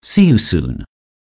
away_activated.wav